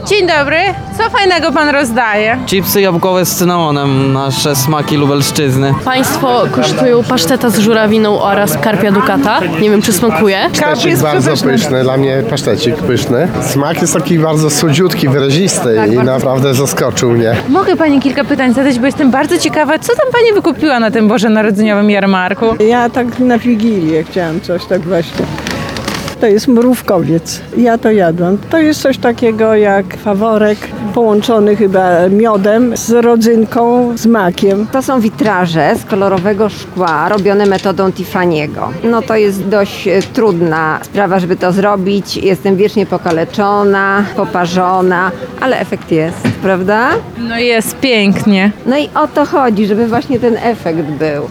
Państwo kosztują pasztet z żurawiną oraz karpia dukata – mówią wystawcy. – Karp jest przepyszny, pasztecik też – przyznają uczestnicy jarmarku.